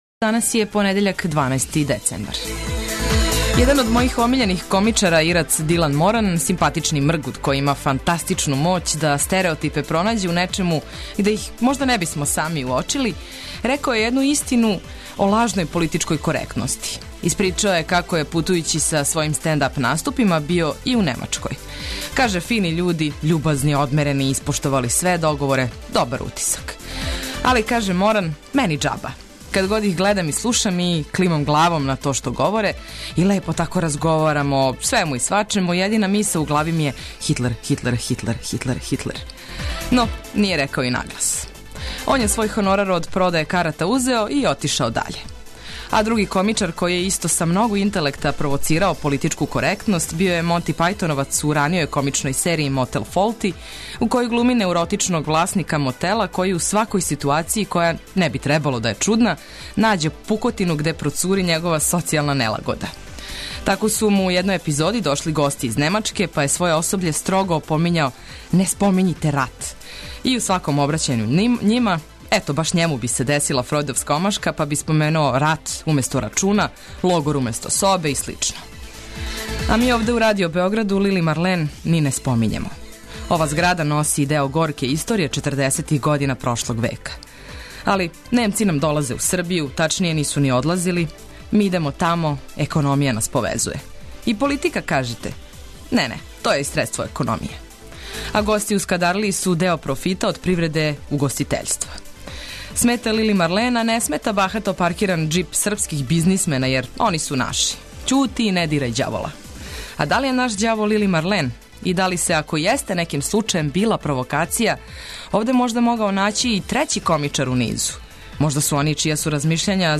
Битне информације за организовање дана, теме које подстичу размишљање и музика за лепше буђење - све то вас очекује у нашем јутру!
Пратимо дешавања широм Србије, спортске, сервисне информације, али пре свега прве сате јутра улепшавамо уз много добре музике!